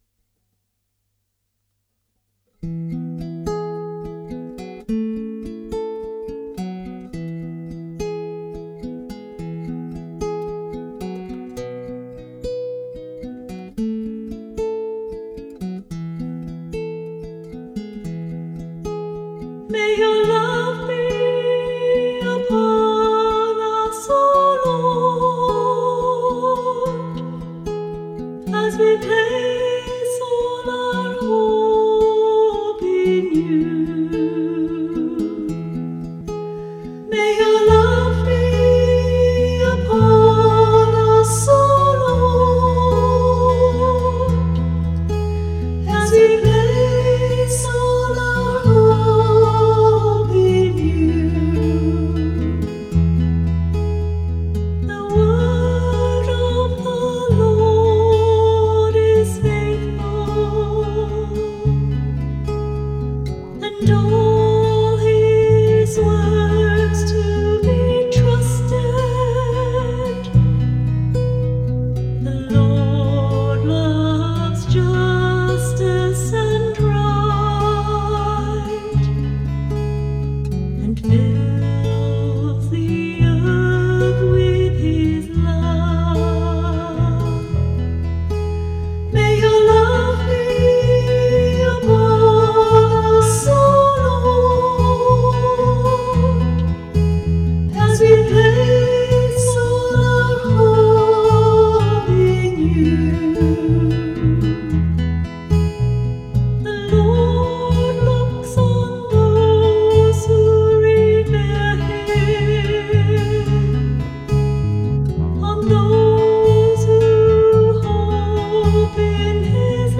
Music by the Choir of Our Lady of the Rosary RC Church, Verdun, St. John, Barbados.